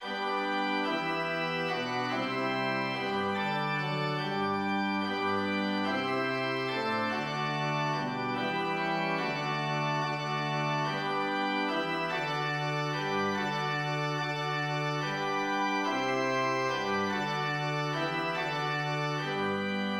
Lutheran hymn
\layout { indent = 0\cm \set Score.tempoHideNote = ##t \context { \Score \remove "Bar_number_engraver" } } { \new ChoirStaff << \new Staff { \tempo 2=72 \partial 2 \clef violin \key g \major \time 3/2 \set Score.midiInstrument = #"church organ" << { g'2 | g'2 fis'4 e'2 g'4 | a'2 g'2 \breathe g'2 | g'2 a'4 fis'2 d'4 | g'2 fis'2 \breathe fis'2 | g'2 g'4 a'2 b'4 | a'2 a'2 \breathe b'2 | c''2 b'4 a'2 g'4 | a'2 g'2 \bar "|."